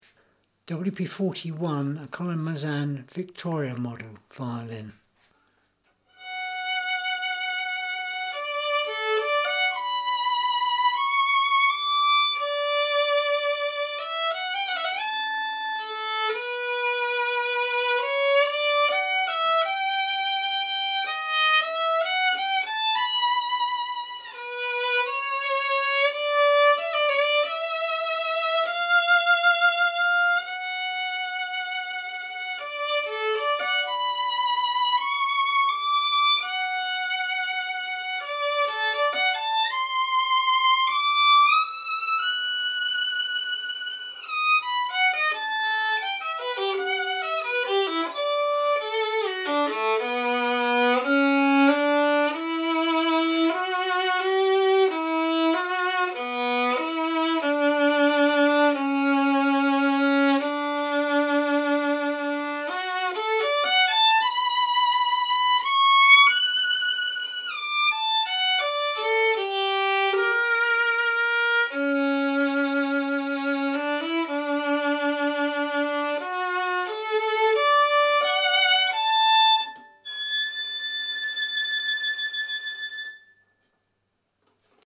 WP41 - Collin-Mezin violin